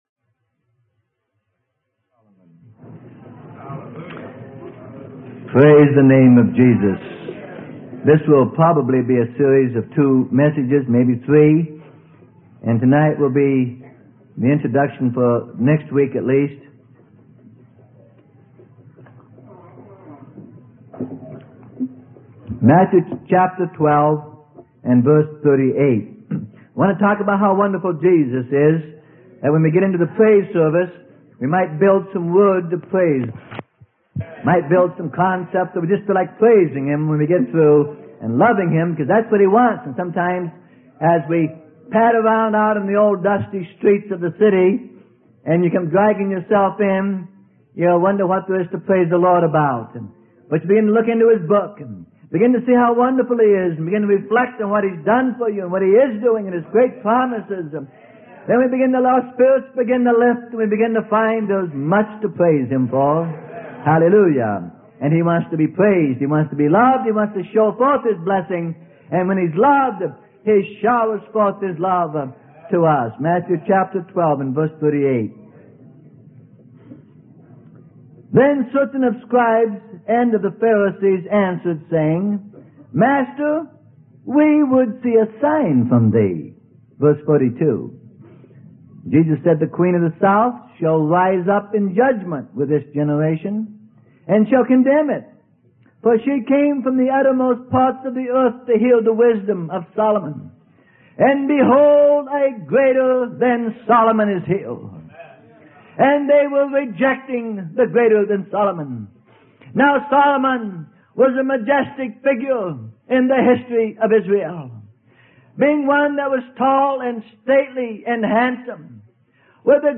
Sermon: The Greater Solomon - Freely Given Online Library